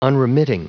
Prononciation du mot unremitting en anglais (fichier audio)
Prononciation du mot : unremitting